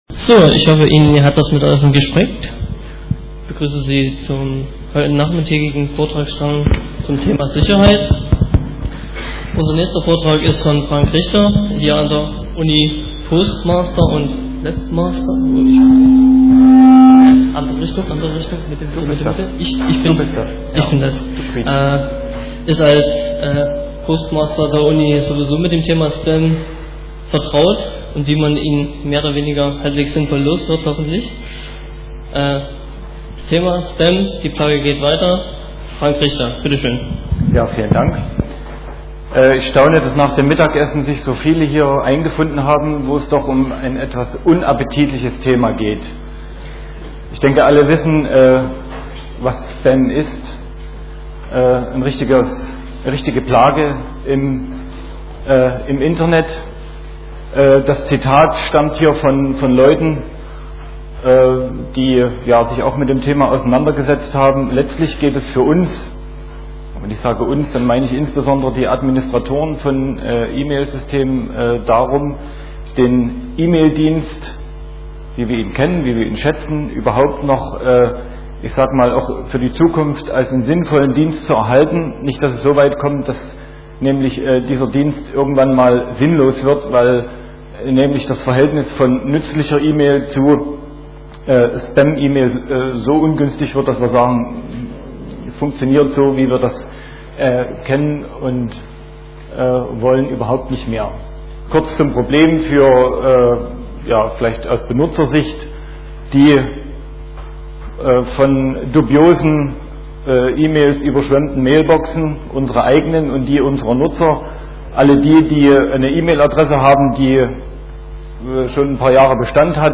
Vortragsmittschnitt